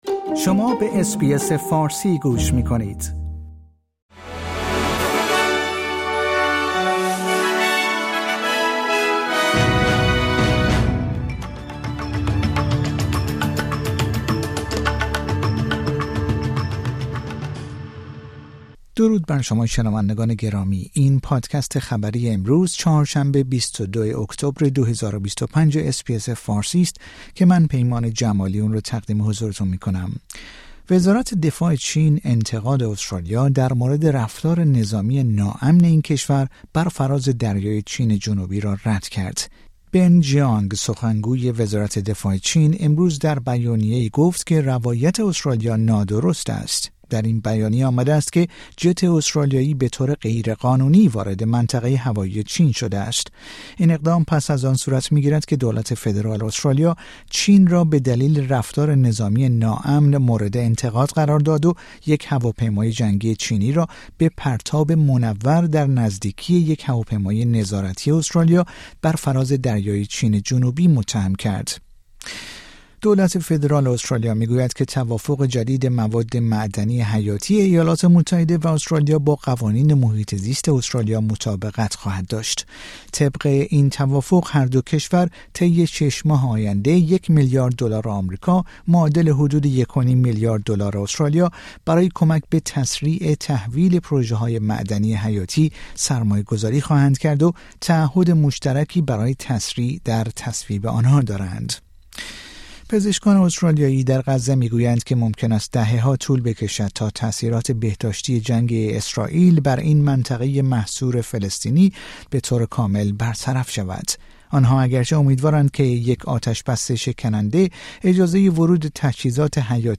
در این پادکست خبری مهمترین اخبار روز چهارشنبه ۲۲ اکتبر ارائه شده است.